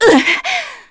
Artemia-Vox_Damage_kr_01.wav